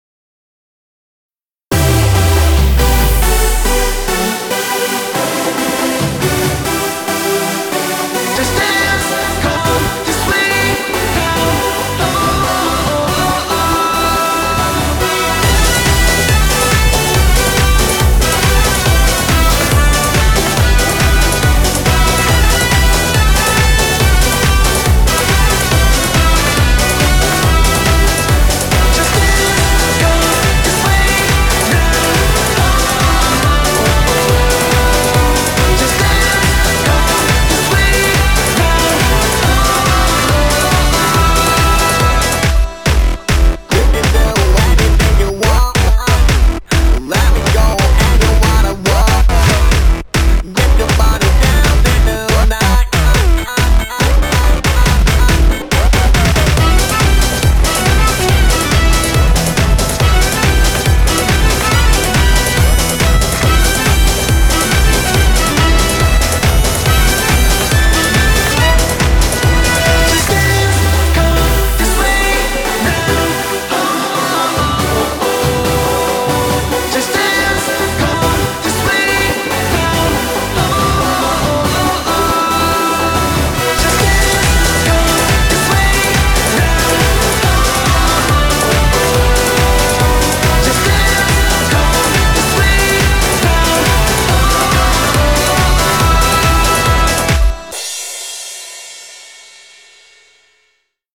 BPM140
Audio QualityPerfect (Low Quality)